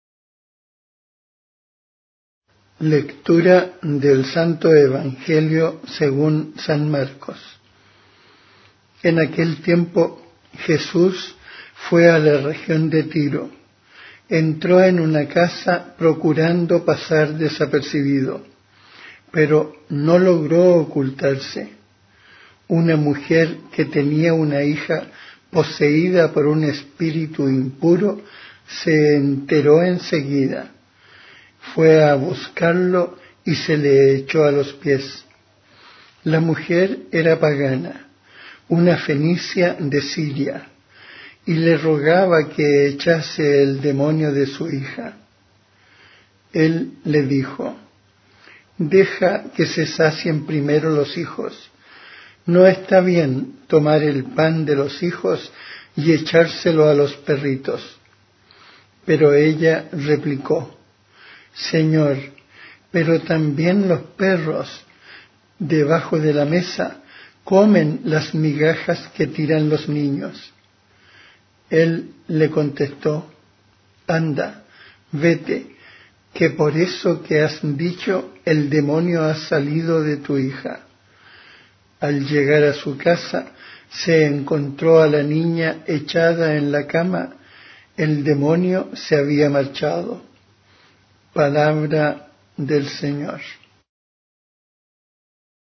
Evangelio en audio.